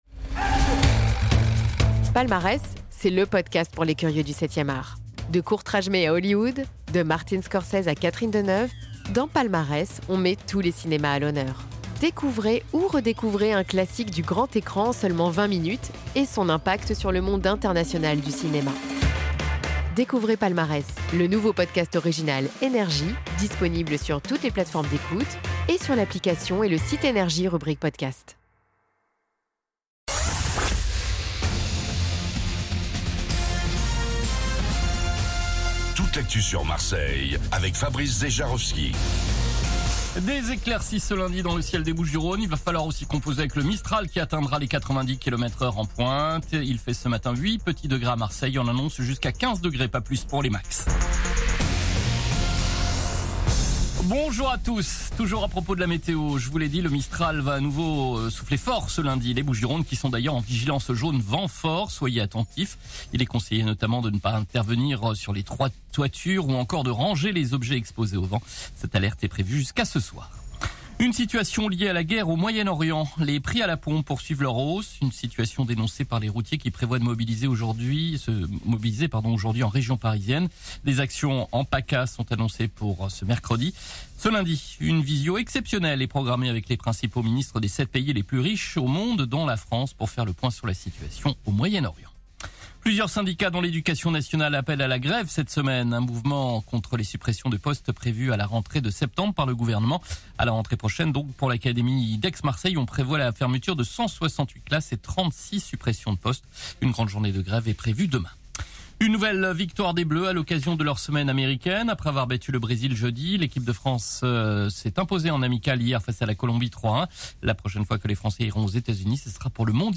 Réécoutez vos INFOS, METEO et TRAFIC de NRJ MARSEILLE du lundi 30 mars 2026 à 07h00